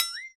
playerShortJump.wav